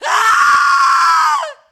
scream_woman_4.ogg